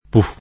vai all'elenco alfabetico delle voci ingrandisci il carattere 100% rimpicciolisci il carattere stampa invia tramite posta elettronica codividi su Facebook puff [ puf ] escl. — voce onomatopeica per imitare rumori vari